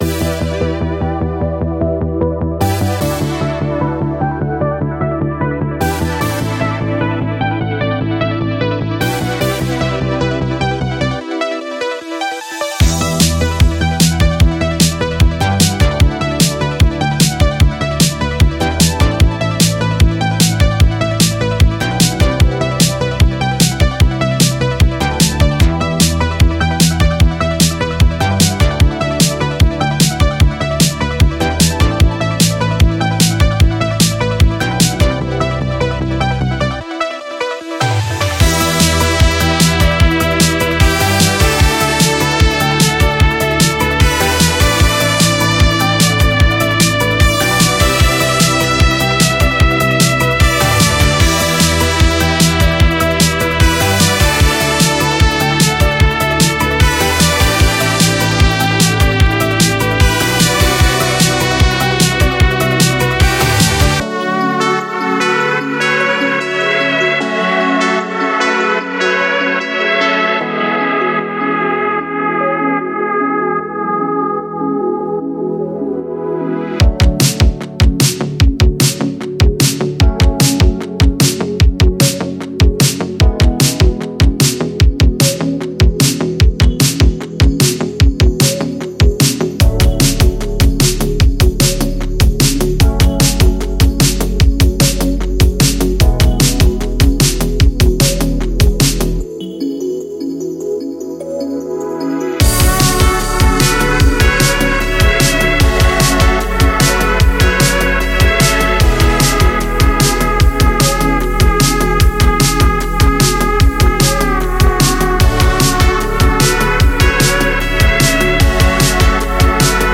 • 15 x Mixed & Mastered FullMix/Preview Demos – (.WAVs)